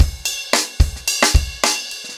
Framework-110BPM_1.9.wav